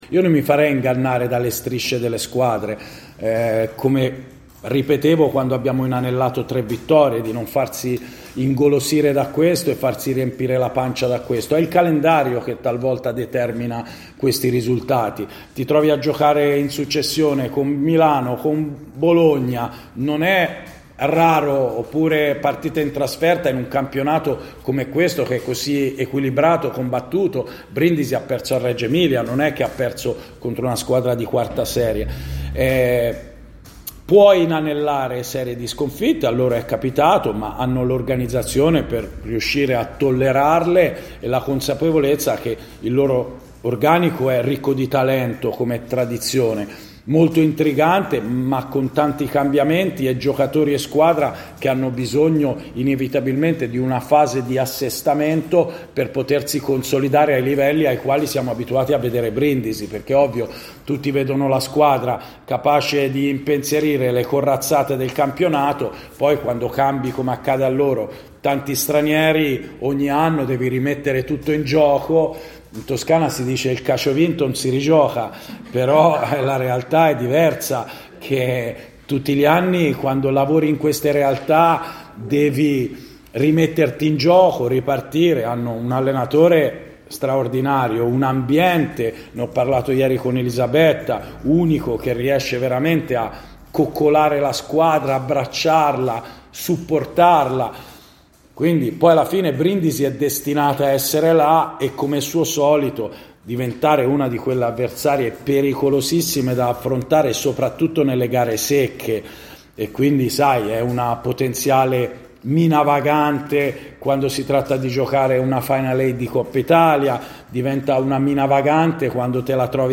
ai nostri microfoni, ci presenta il match.